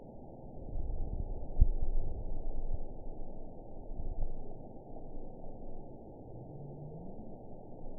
event 921941 date 12/23/24 time 04:06:46 GMT (11 months, 1 week ago) score 8.97 location TSS-AB03 detected by nrw target species NRW annotations +NRW Spectrogram: Frequency (kHz) vs. Time (s) audio not available .wav